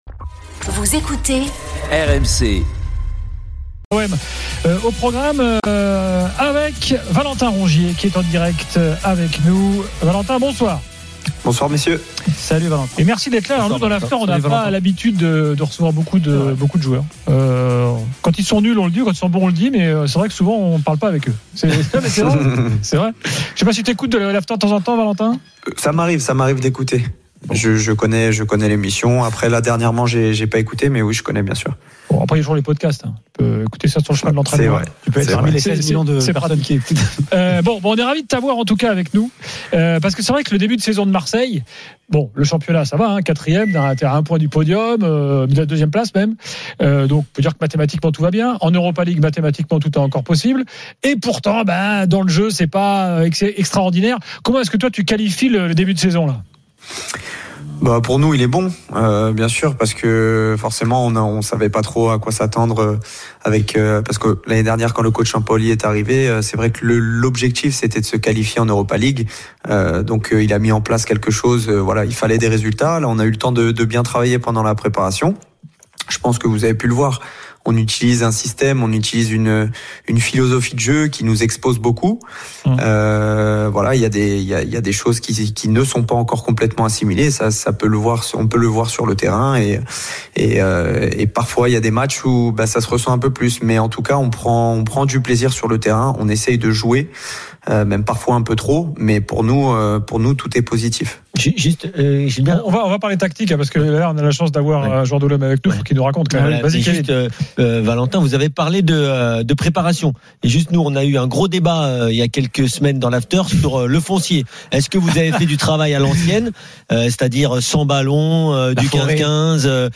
Le Top de l'After Foot : Valentin Rongier, milieu de l'OM, invité de l'After – 15/11
Chaque jour, écoutez le Best-of de l'Afterfoot, sur RMC la radio du Sport !